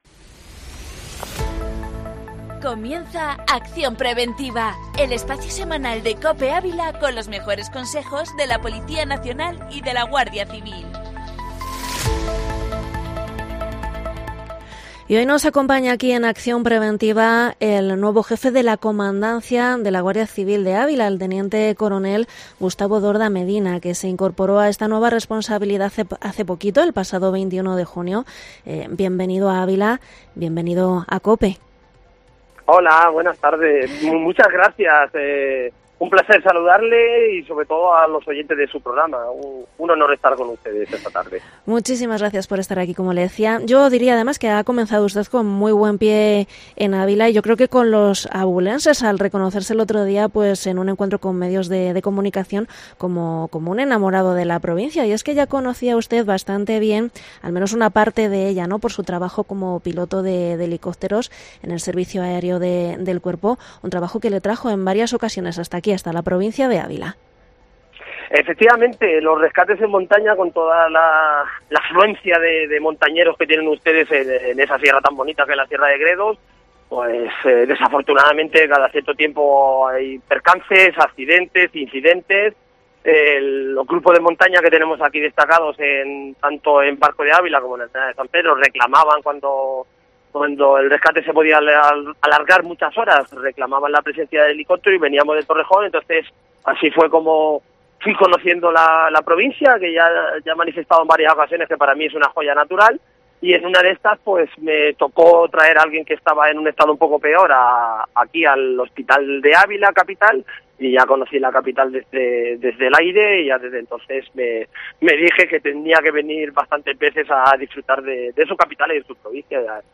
Entrevista al nuevo jefe de la Comandancia de la Guardia Civil de Ávila